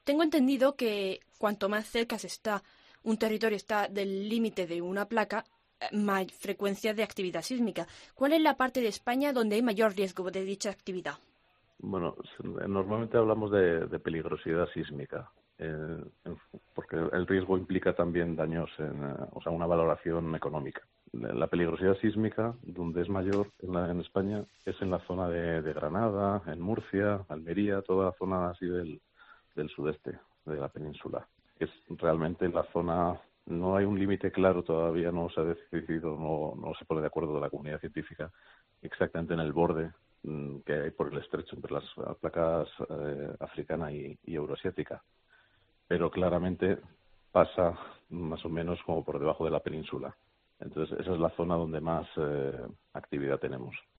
El sismólogo